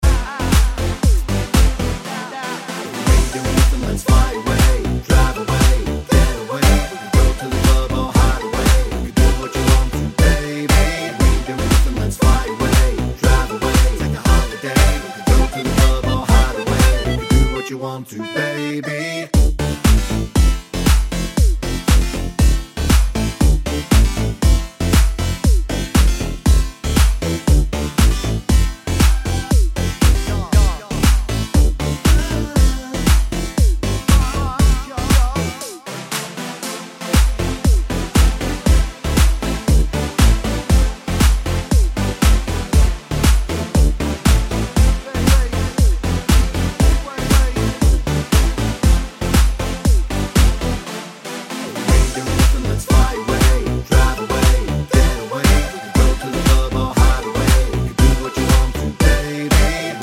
For Solo Rapper R'n'B / Hip Hop 3:24 Buy £1.50